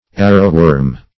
Arrowworm \Ar"row*worm`\, n. (Zool.)